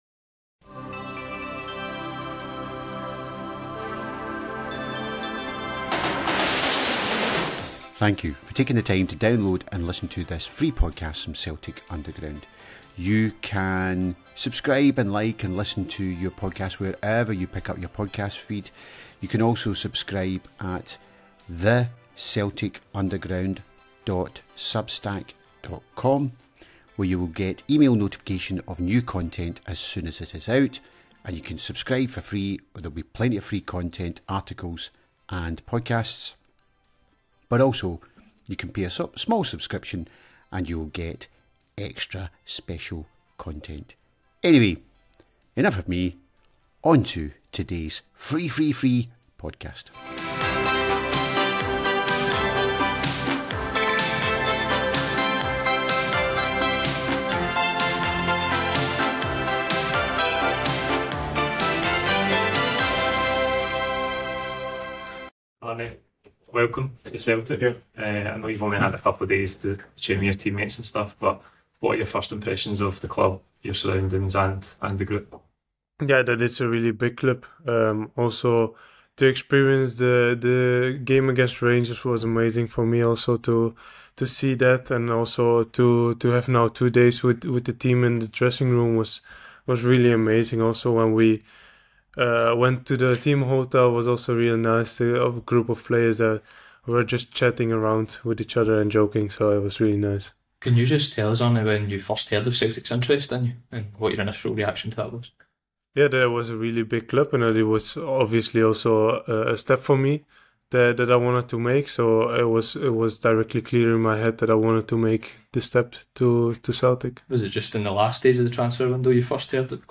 We have the audio and video from the meetings with the fan media.